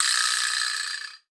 PVIBRASLAP.wav